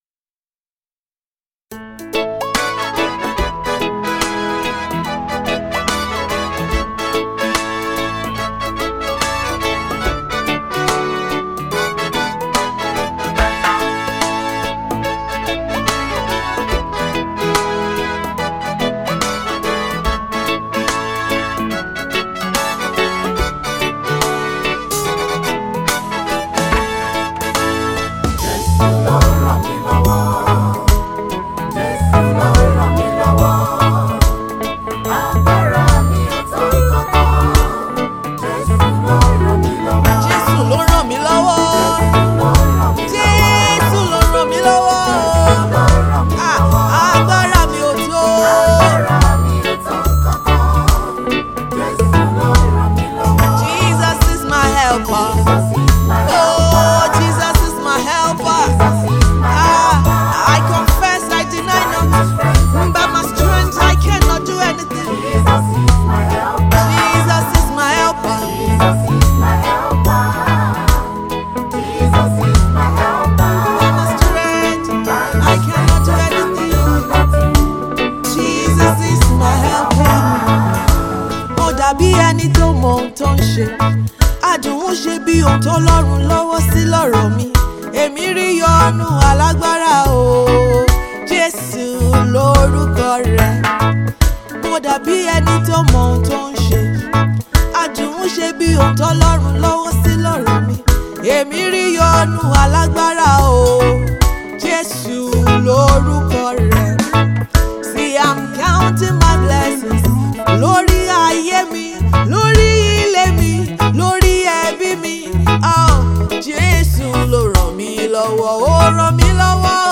SOUL-STIRRING GOSPEL REGGAE SINGLE
Renowned gospel artiste